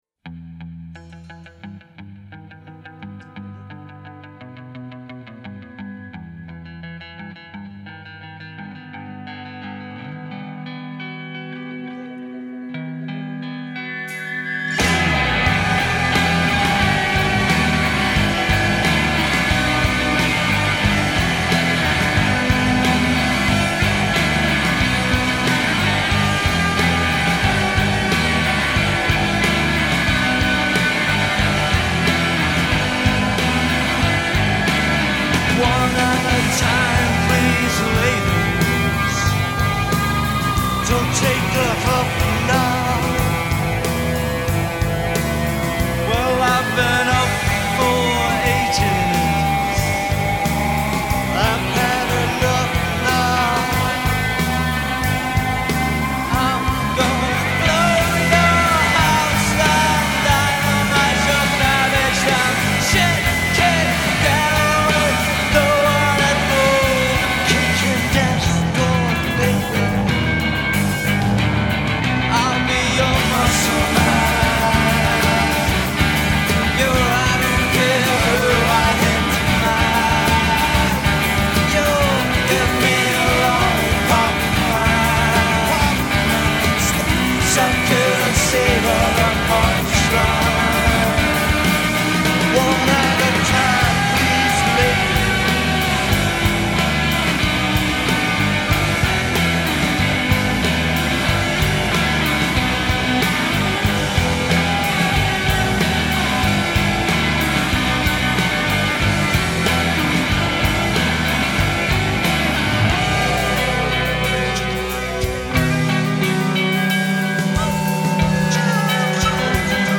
fuzzbox gets a good workout
bass guitar